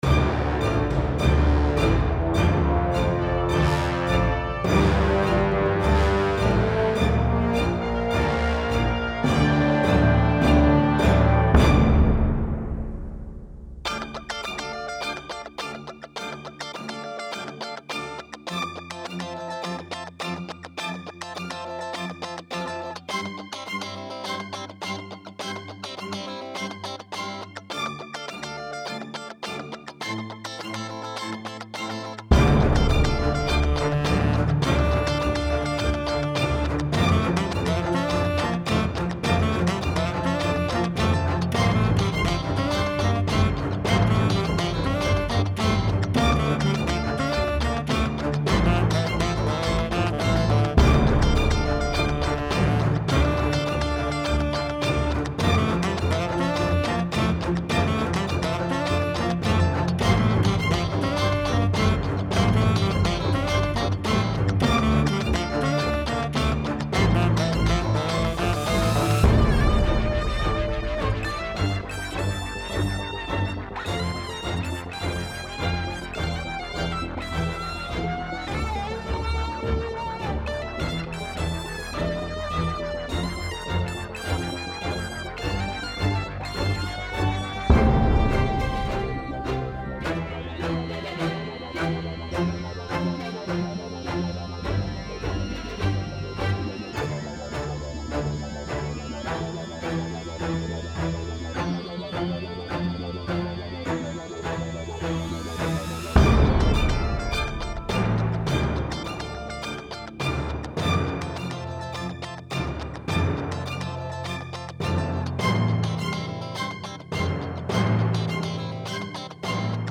Style Style Orchestral, Pop, Soundtrack
Mood Mood Bright, Cool, Epic +1 more
Featured Featured Bass, Brass, Cello +4 more
BPM BPM 104